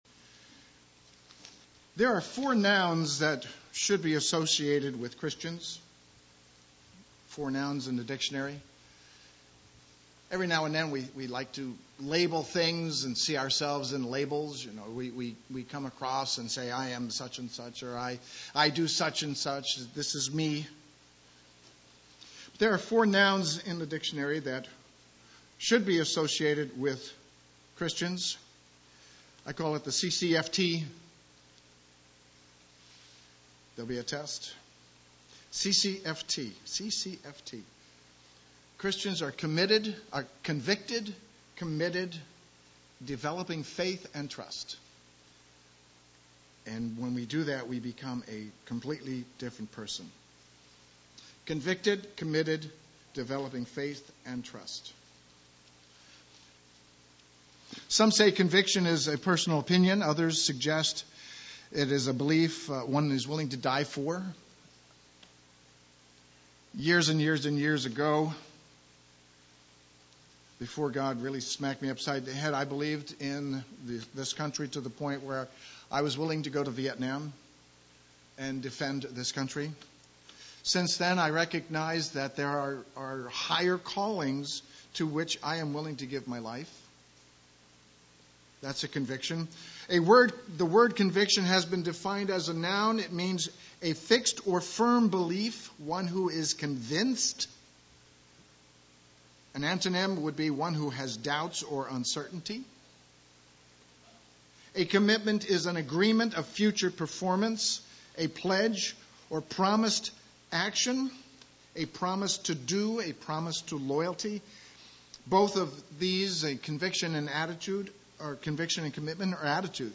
Sermons
Given in Eureka, CA